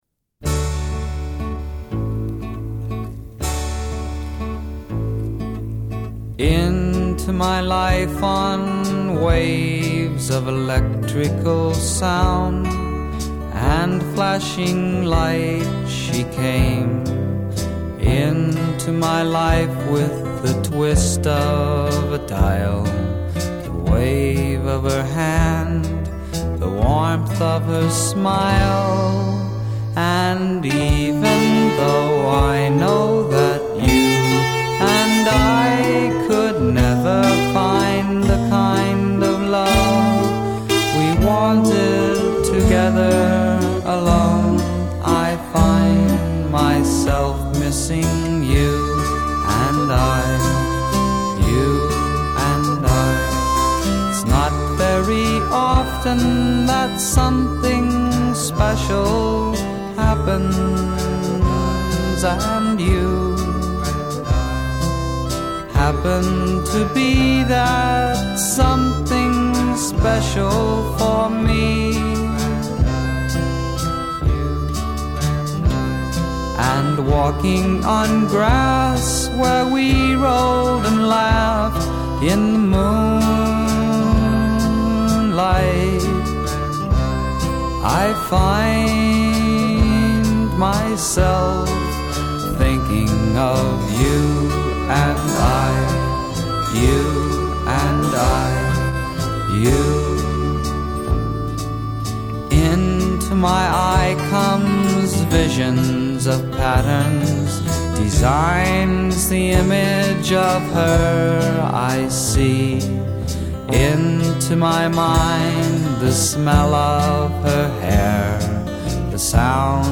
fleurons de la musique psychédélique west-coast.